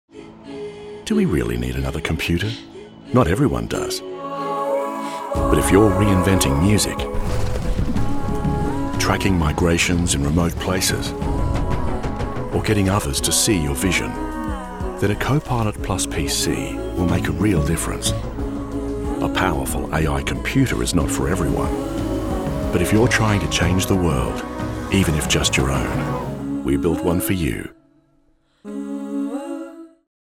Australian Demo